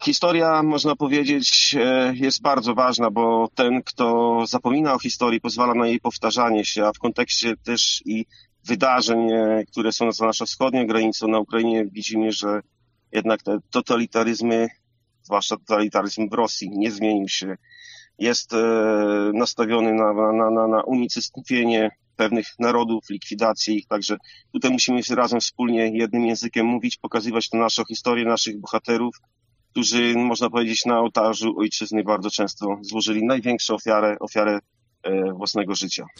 Burmistrz dodaje, że nasza historia jest bardzo istotna: